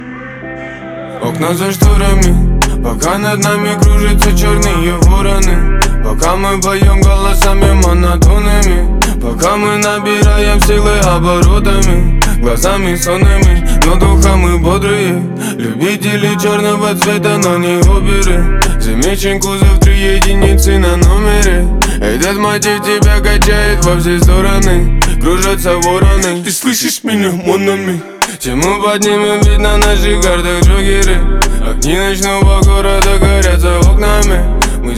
Жанр: Рэп и хип-хоп / Русские